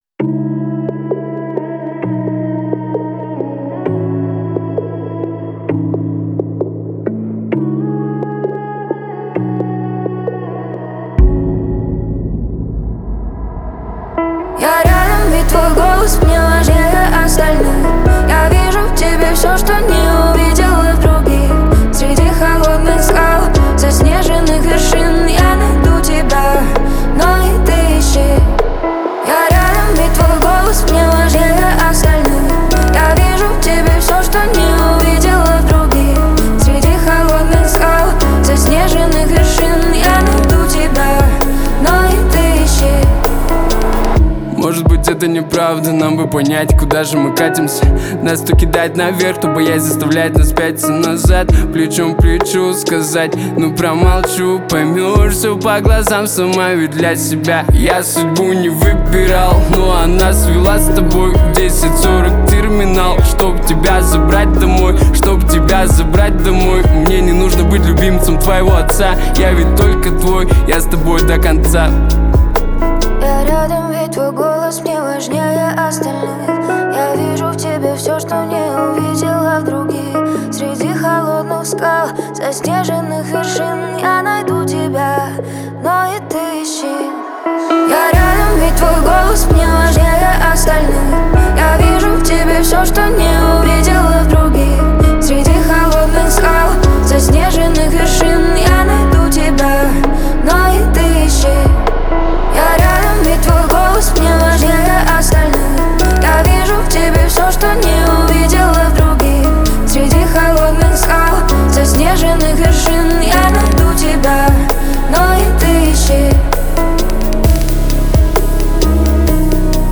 лиричные песни